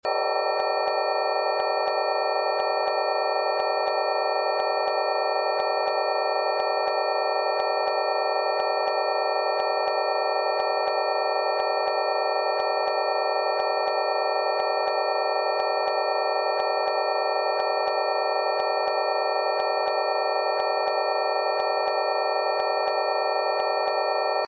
Rife frequencies for mold and sound effects free download